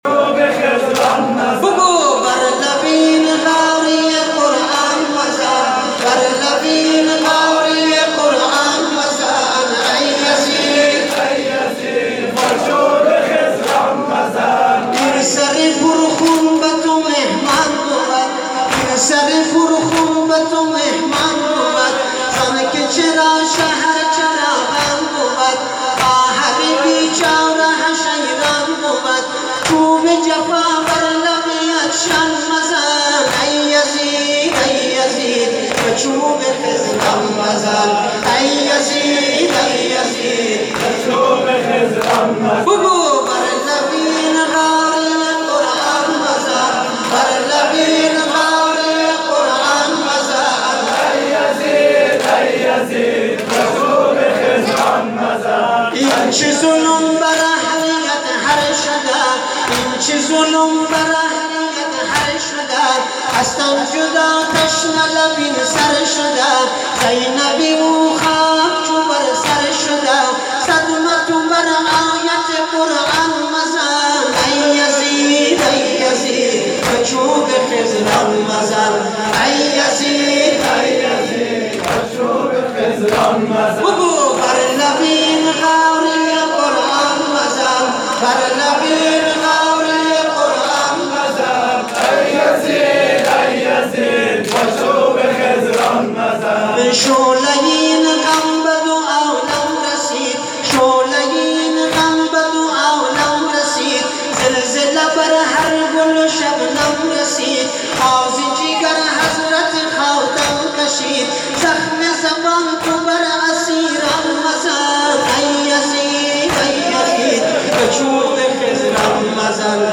برچسب ها: شیعیان افغانستان نوحه محرم 1403 مشهد